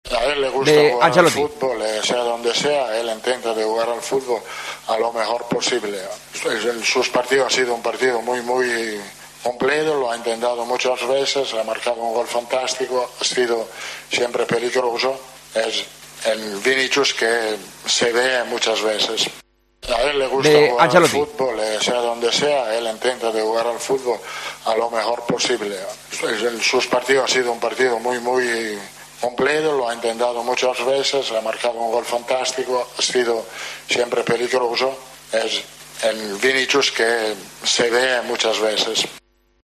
"El equipo está creciendo. Hoy hemos cumplido porque estos partidos tienes todo para perder y nada para ganar. No hemos ganado con tranquilidad, pero lo hemos controlado bastante bien", dijo en rueda de prensa.